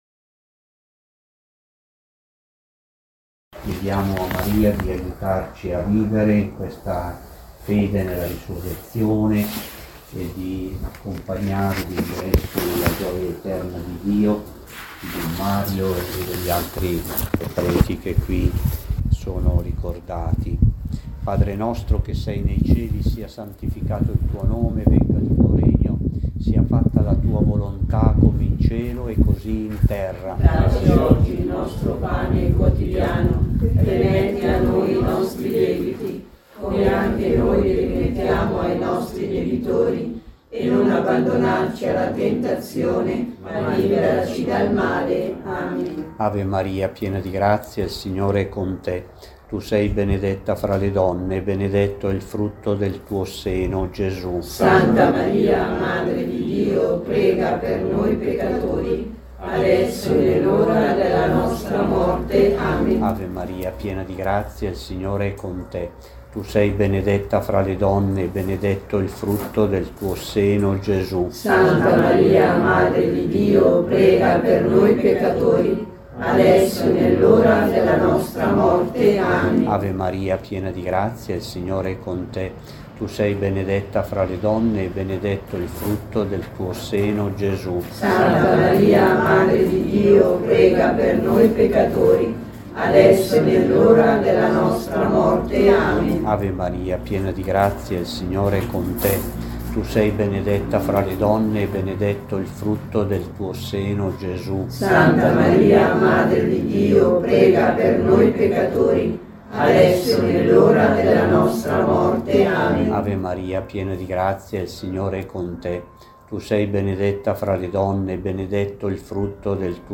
Mons. Delpini ha pregato un mistero del Santo Rosario e prima della Benedizione ha pregato e ringraziato il Signore:
Preghiera di una decina del Rosario al Cimitero <